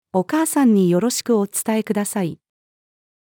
「お母さんによろしくお伝えください。」-female.mp3